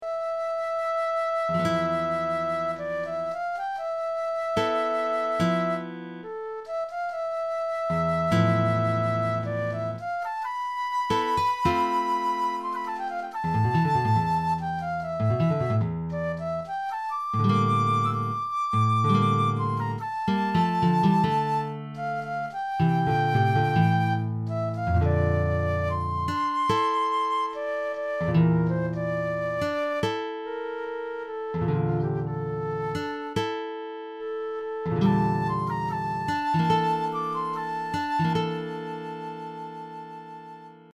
for Flute and Guitar